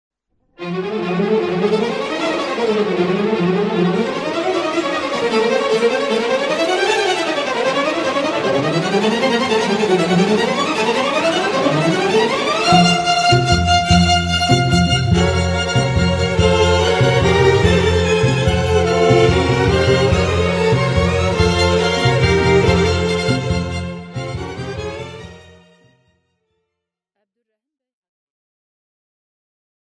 Аудиокнига Bomba | Библиотека аудиокниг